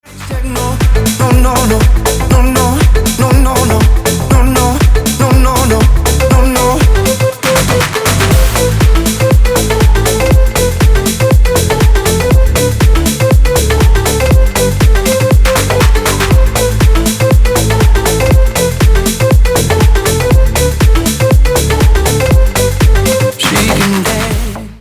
Скачать бесплатно танцевальный рингтон 2024 для мобильного